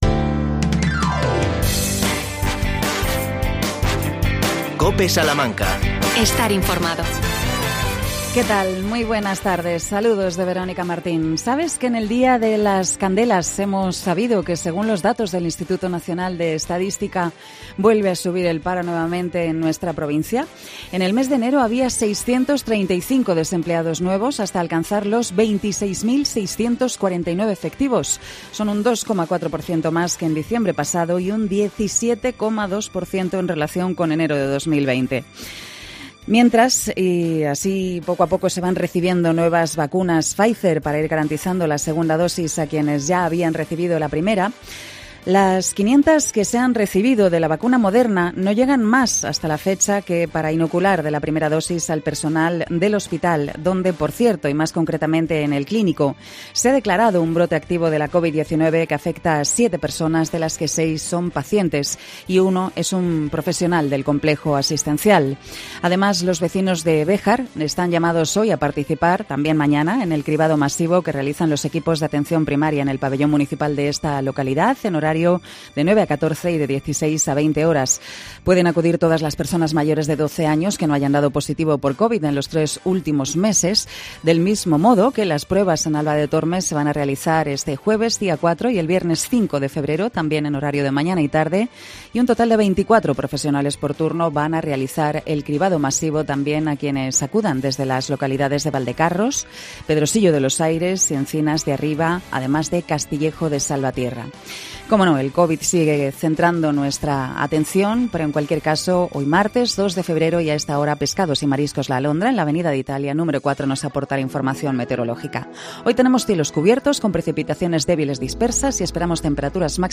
AUDIO: El concejal de economía Fernando Rodríguez informa sobre los Presupuestos Municipales de Salamanca